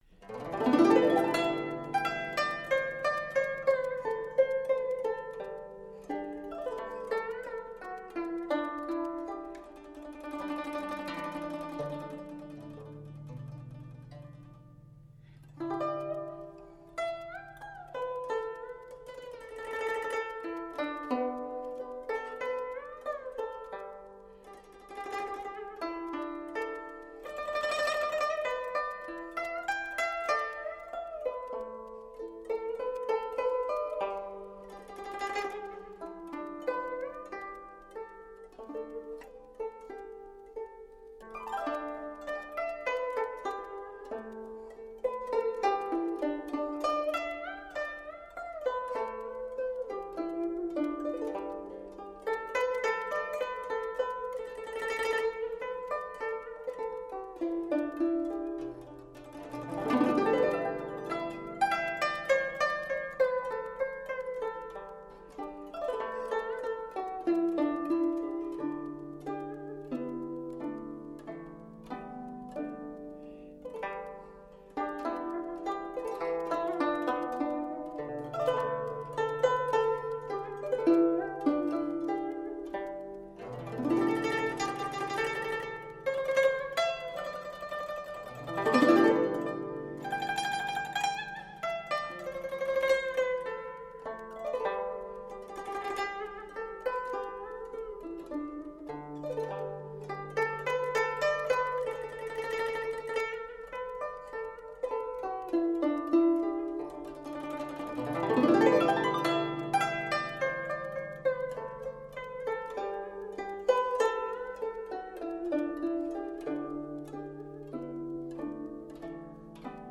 搜罗国内耳熟能详的怀旧影视歌曲，古朴自然的纯古筝，让这些经典调子成为难忘的旋律。